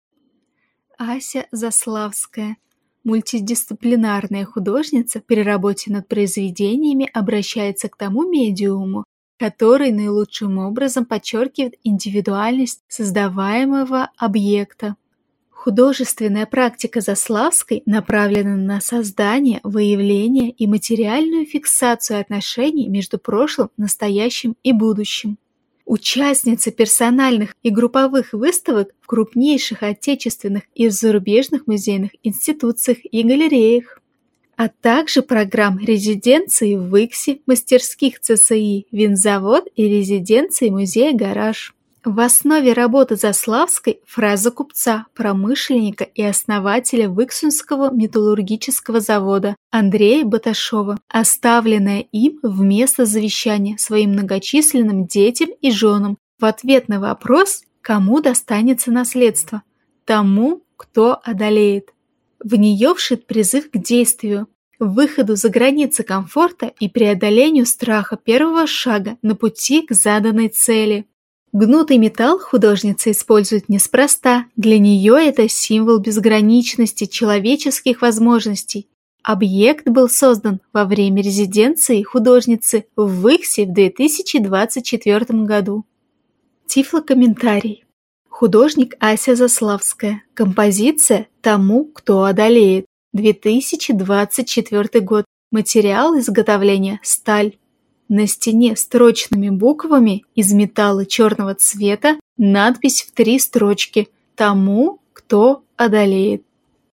Тифлокомментарий к картине Аси Заславской "Тому, кто одолеет"